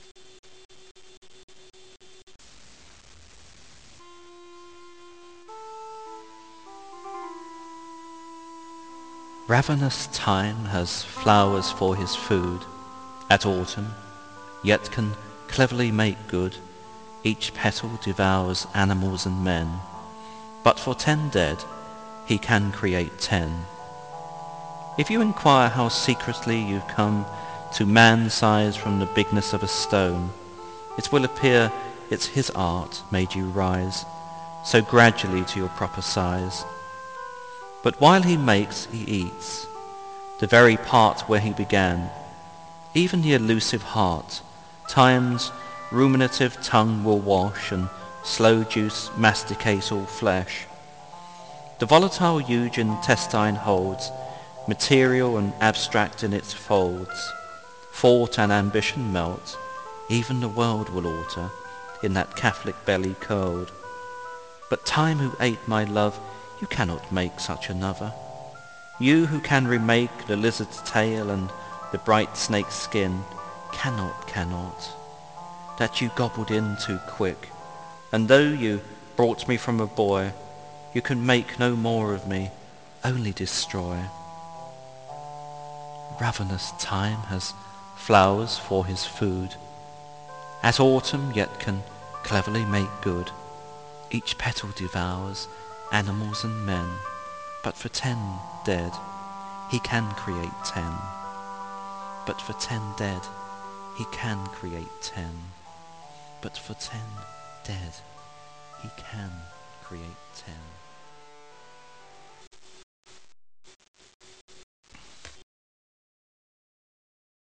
Keith Douglas was a British soldier poet who died in action aged 24 in Normany in 1944 Recited
with original music